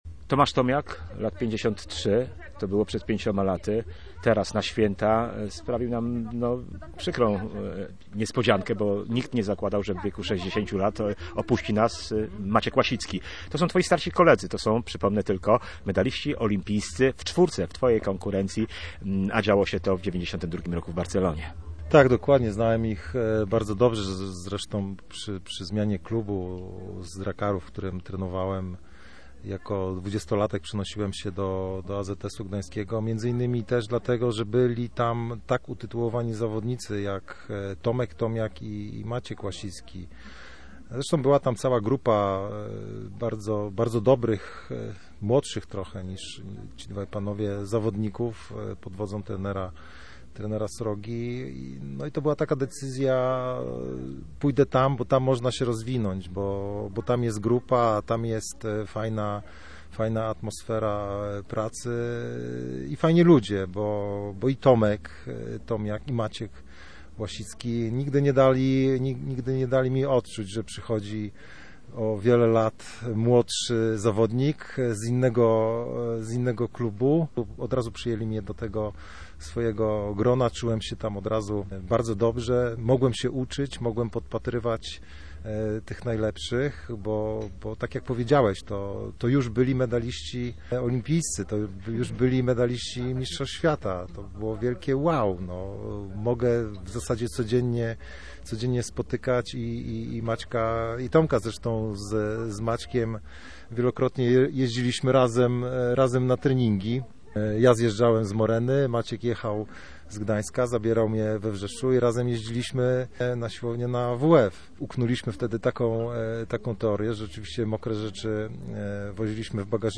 Pięciokrotny olimpijczyk, mistrz z Pekinu z 2008 roku, czterokrotny mistrz świata w audycji „Z boisk i stadionów” wspominał zmarłego 19 grudnia Macieja Łasickiego oraz nieobecnego od pięciu lat Tomasza Tomiaka.